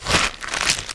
MISC Soft Plastic, Scrape 02.wav